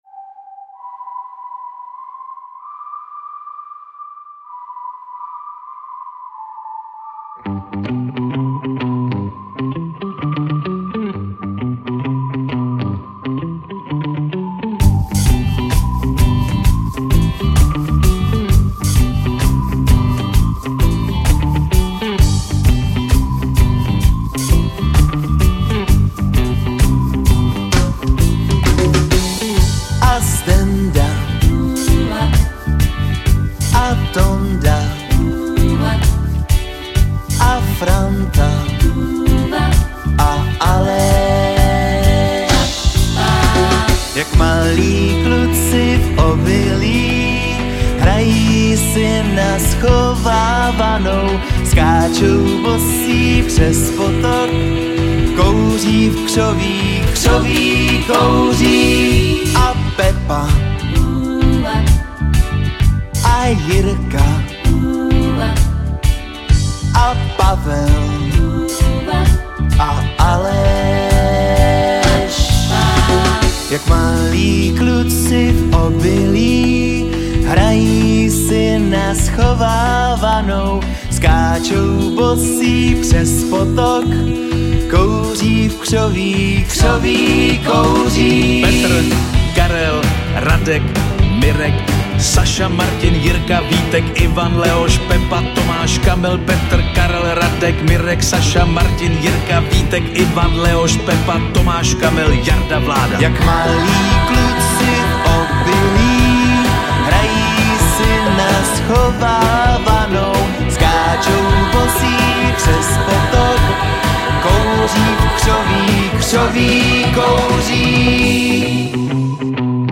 Žánr: Pop
veselý nekomplikovaný pop rock